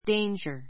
danger 中 A2 déindʒə r デ インヂャ 名詞 ❶ 危険(な状態) 関連語 「危険な」は dangerous .